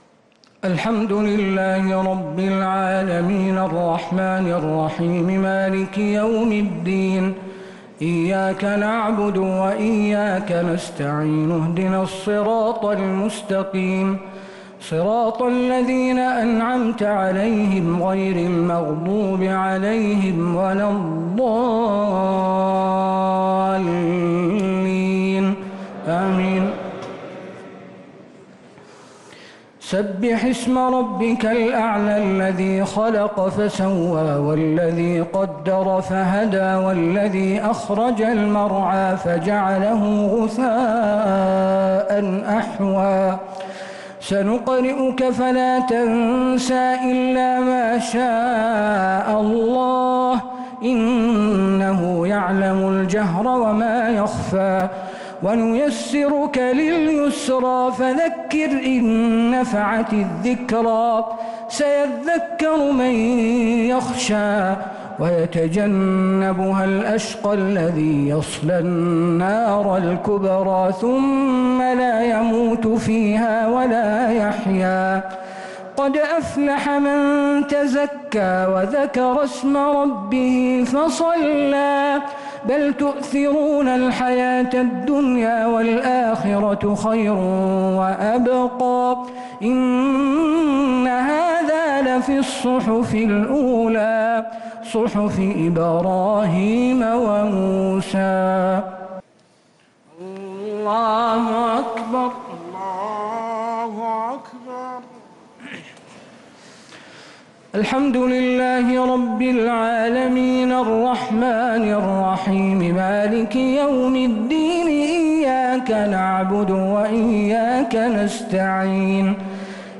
الشفع و الوتر ليلة 14 رمضان 1446هـ | Witr 14th night Ramadan 1446H > تراويح الحرم النبوي عام 1446 🕌 > التراويح - تلاوات الحرمين